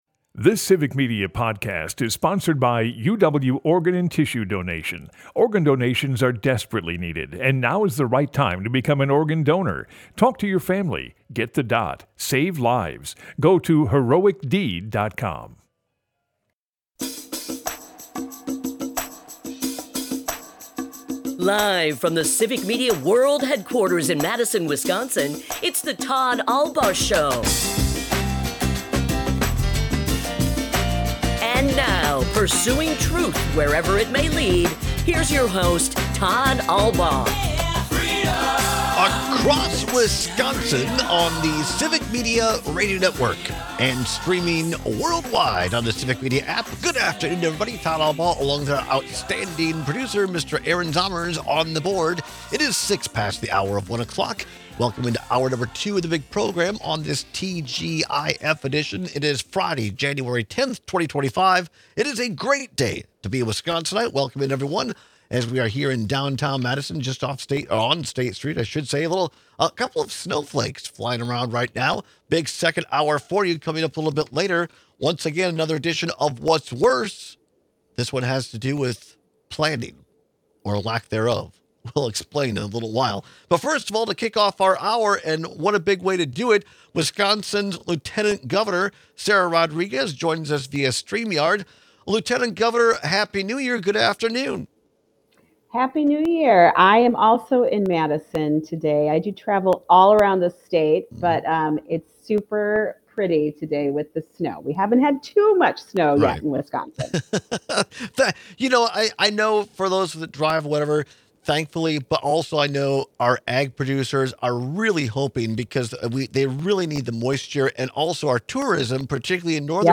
Broadcasts live 12 - 2p across Wisconsin.
We end today’s show with a clip from the Joe Rogan Experience.